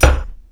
Shield5.wav